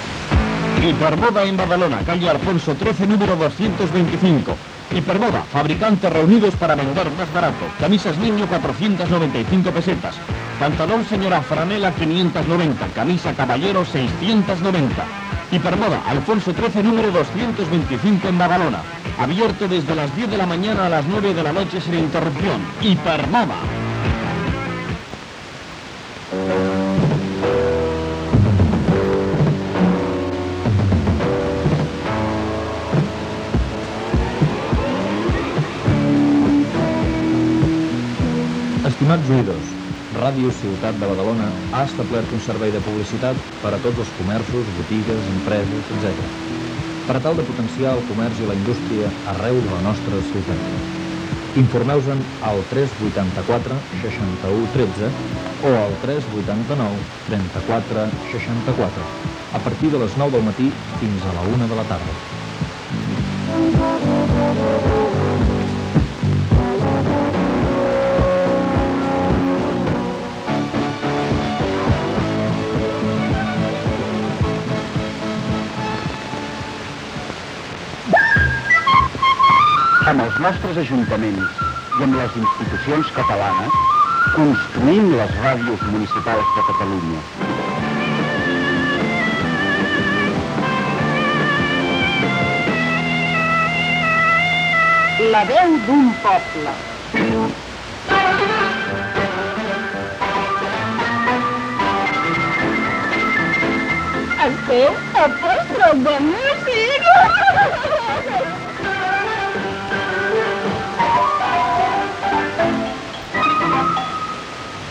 Publicitat, avís de la creació d'un servei de publicitat de l'emissora, indicatiu EMUC, indicatiu del programa.
FM